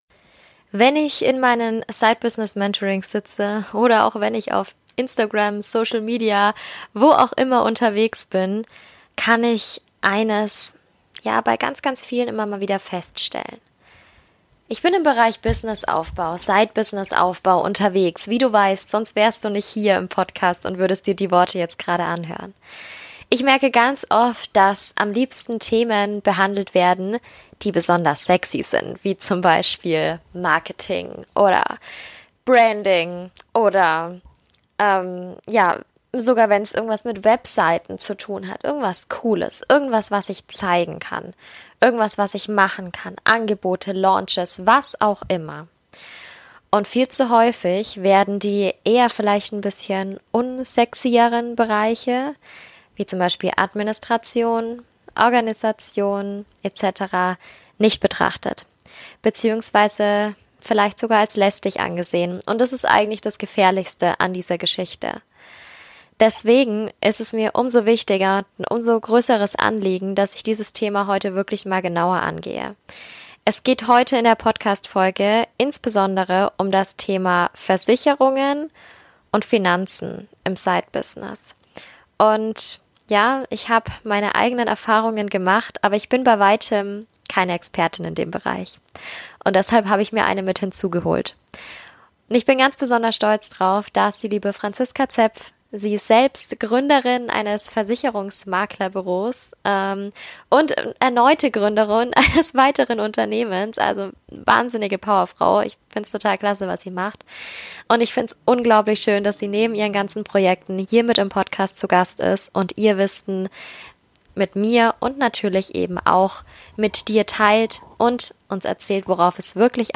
#44 Nebenberuflich gründen, aber sicher! - Interview